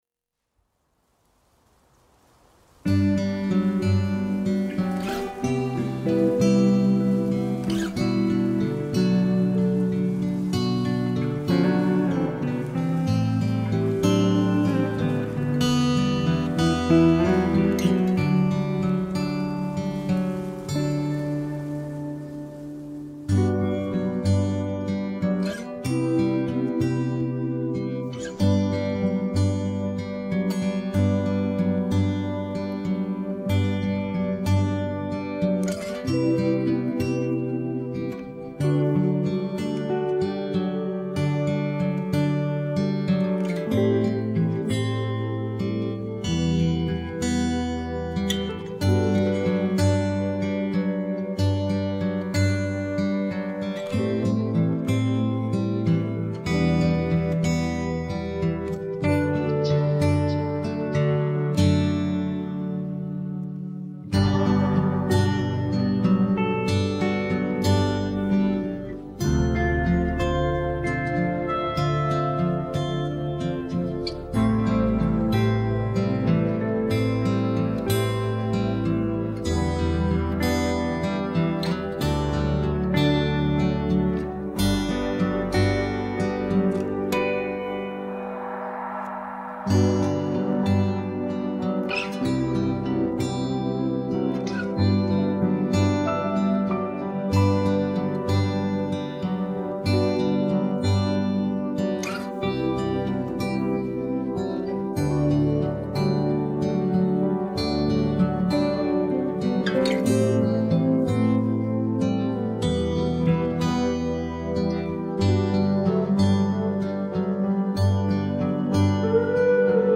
Muzik latar